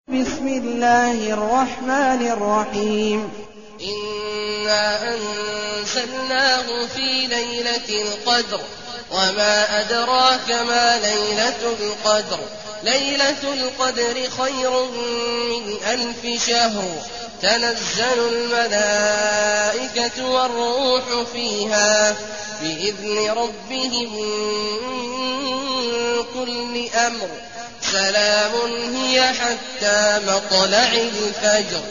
المكان: المسجد الحرام الشيخ: عبد الله عواد الجهني عبد الله عواد الجهني القدر